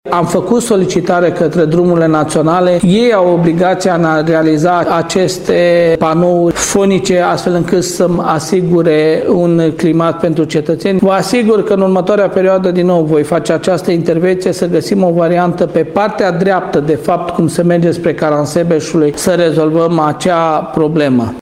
Primarul Călin Dobra spune a transmis solicitări către Drumurile Naționale, care au obligația de a asigura un climat sigur pentru cetățeni.